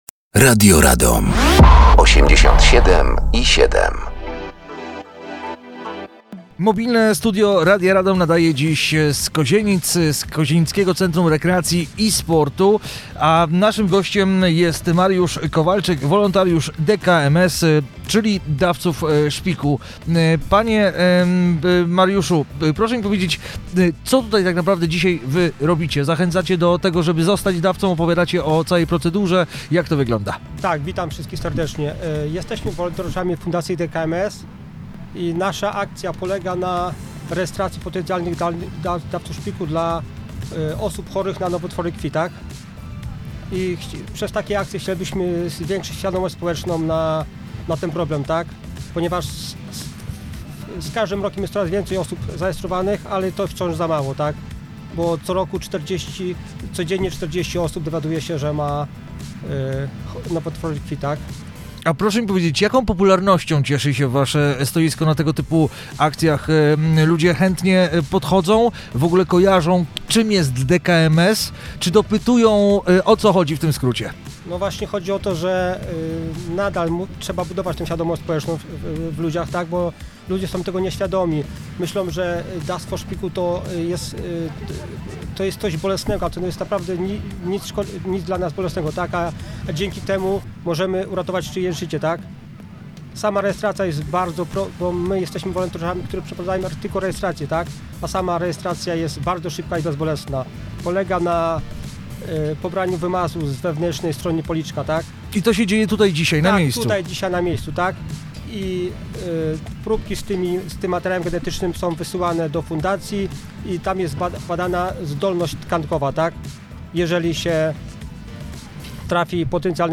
Mobilne studio Radia Radom gości dziś w Kozienicach, podczas 12. edycji Enea Energetyczna Dycha 2025